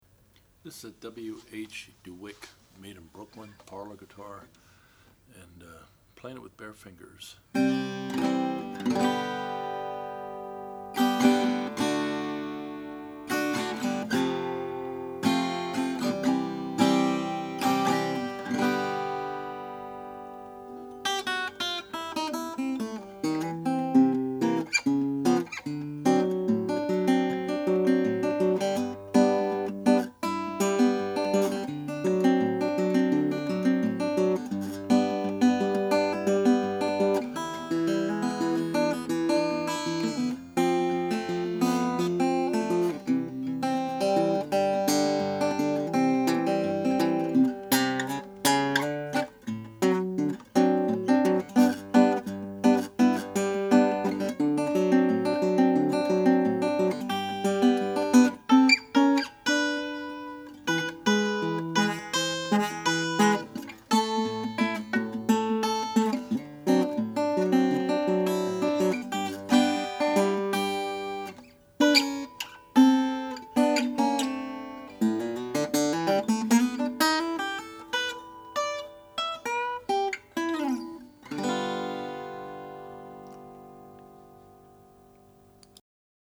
The top is spruce, ladder braced, and adorned with multiple bands of colorful marquetry including a band of green-tinged 'rope' binding around the sound hole.
The guitar plays easily and produces a tone that is full and sweet sounding, with very nice sustain. A true 'parlor' guitar, also suitable for rags and bluesy stuff, and can really ring when played with a pick!